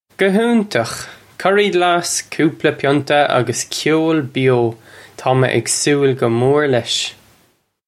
Pronunciation for how to say
Guh hoontukh. Curry glass, koopla pyunta uggus kyole byo: taw may ig sool guh more lesh!
This is an approximate phonetic pronunciation of the phrase.